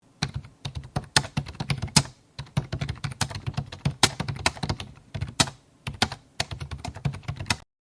Key Strokes Hard
Foley
yt_j9V42y7AwJQ_key_strokes_hard.mp3